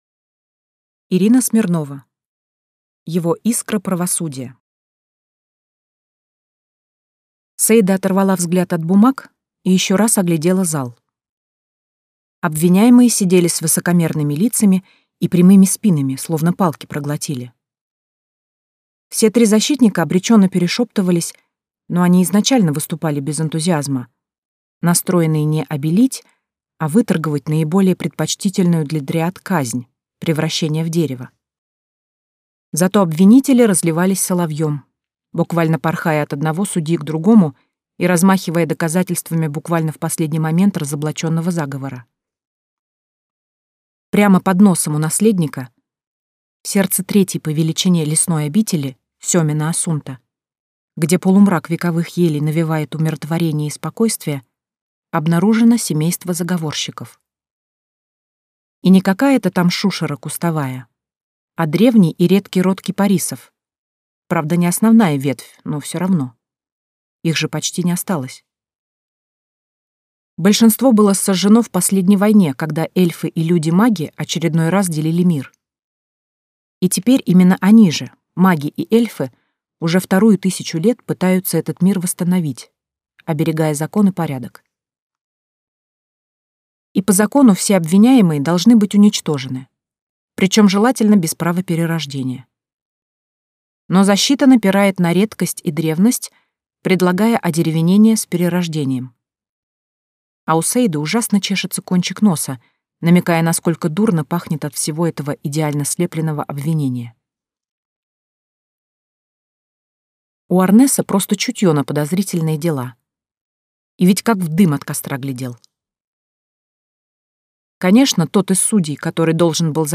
Аудиокнига Его искра правосудия | Библиотека аудиокниг
Прослушать и бесплатно скачать фрагмент аудиокниги